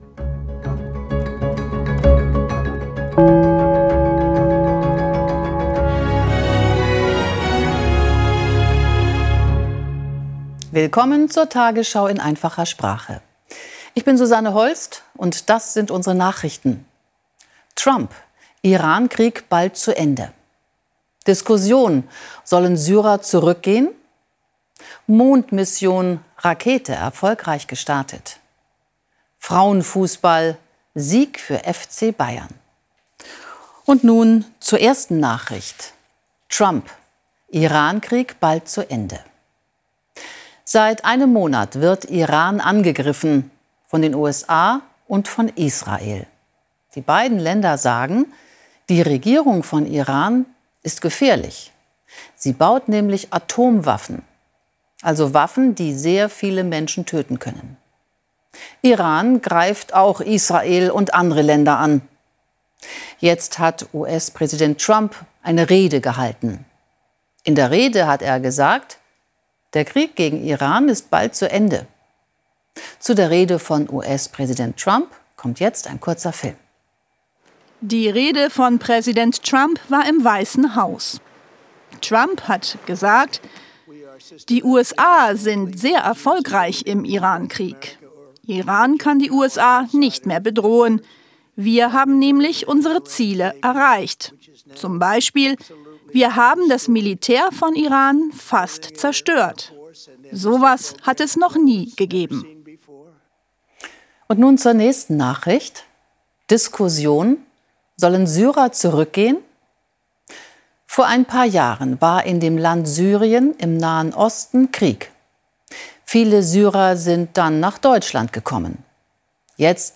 Bildung , Nachrichten